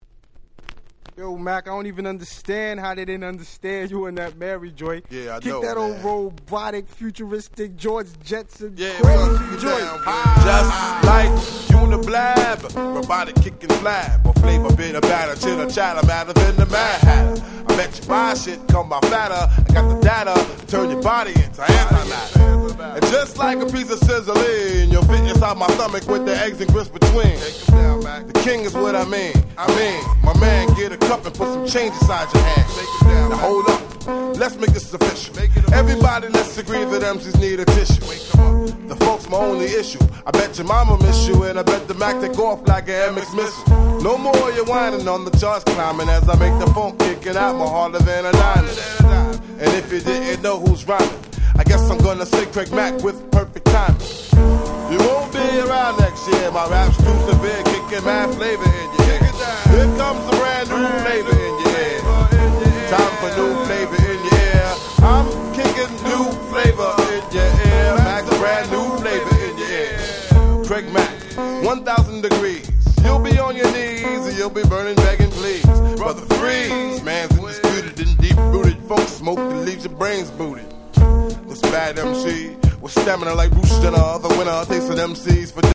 94' Super Hit Hip Hop !!